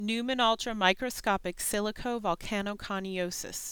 En-us-pneumonoultramicroscopicsilicovolcanoconiosis.ogg.mp3